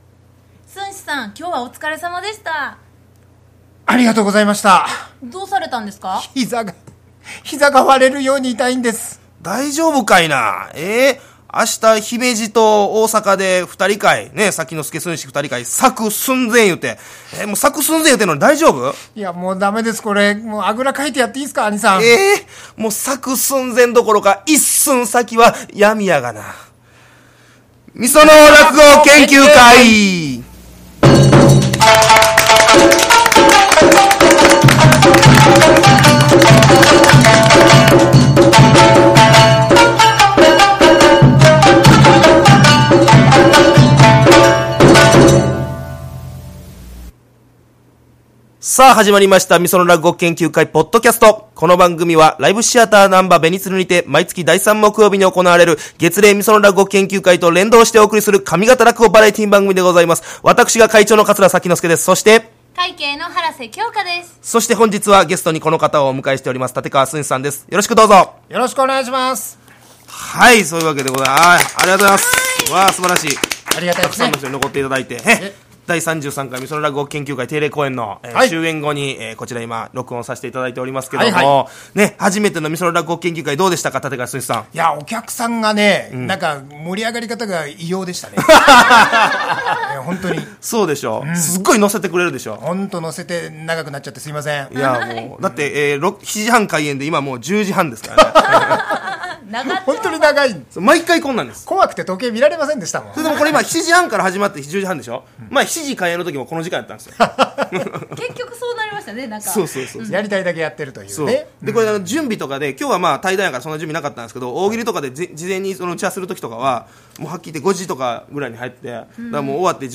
機材トラブルでいつもより短めの放送となります。